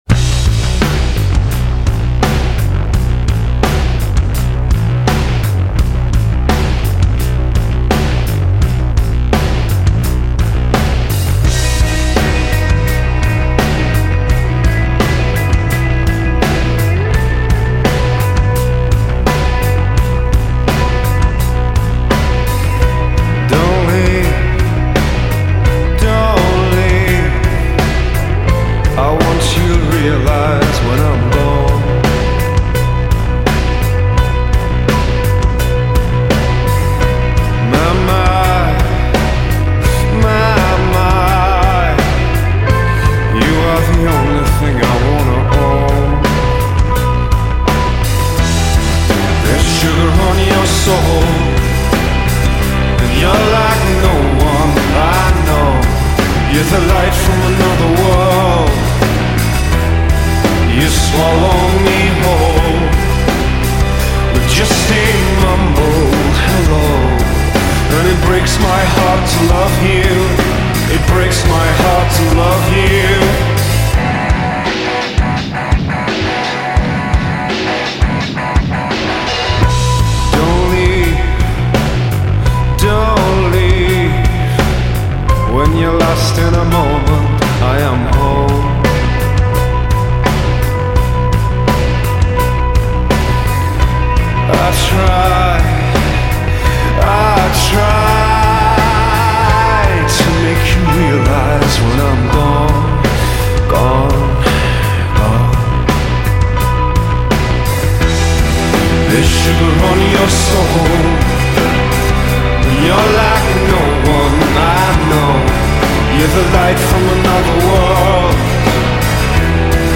broody